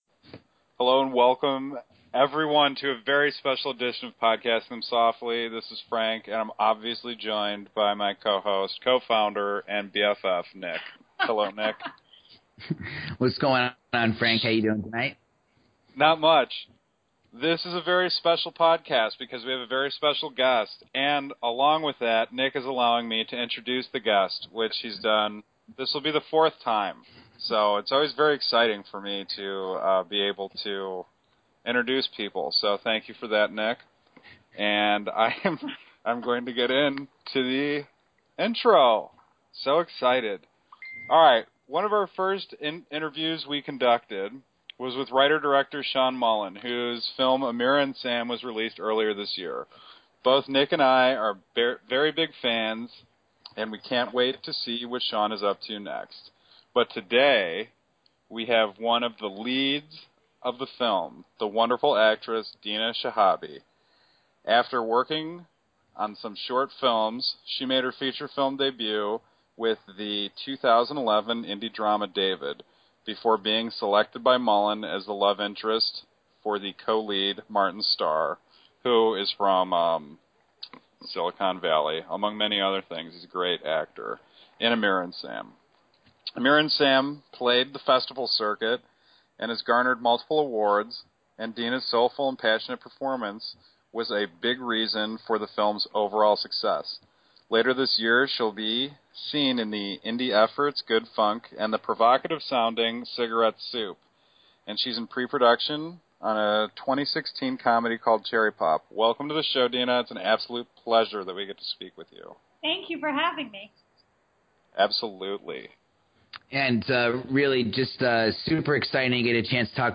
Podcasting Them Softly is proud to present a chat with actress Dina Shihabi, one of the lead stars from Sean Mullin’s award winning indie dramedy AMIRA & SAM. Dina chats with us about her career, her background, the importance of a film like Amira & Sam, and her future projects.